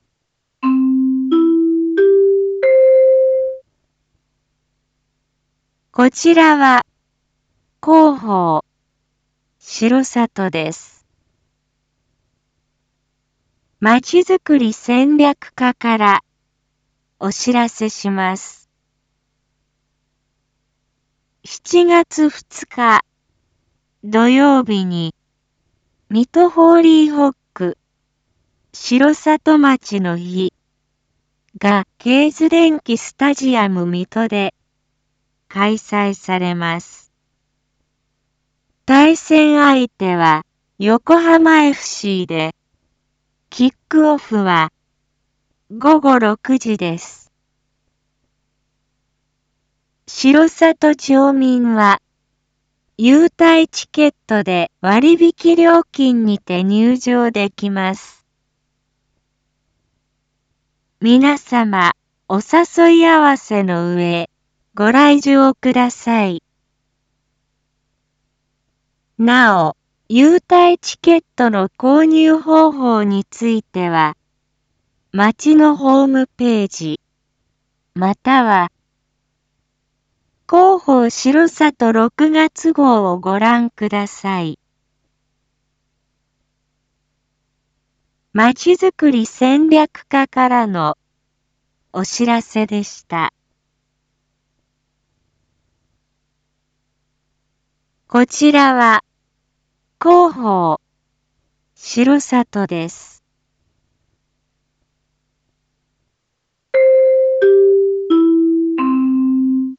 一般放送情報
Back Home 一般放送情報 音声放送 再生 一般放送情報 登録日時：2022-07-01 07:01:51 タイトル：R4.7.1 7時放送分 インフォメーション：こちらは広報しろさとです。